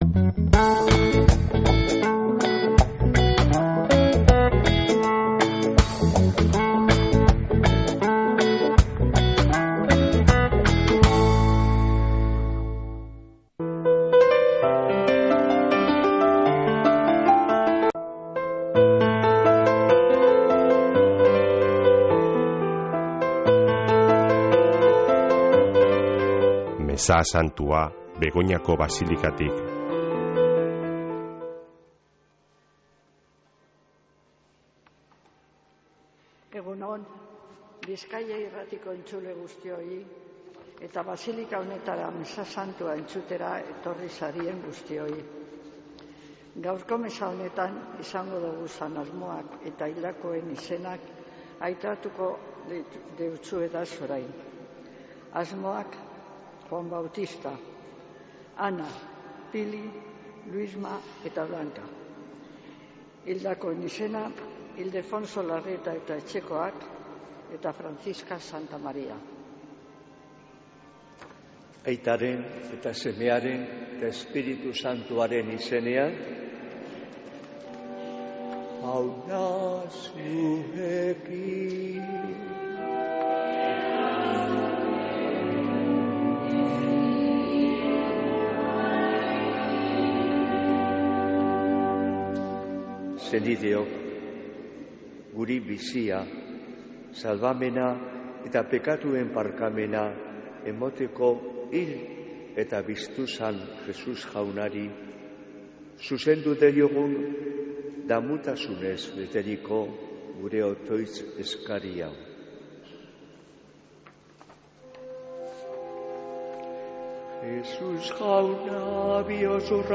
Mezea Begoñako Basilikatik | Bizkaia Irratia
Mezea (25-02-27)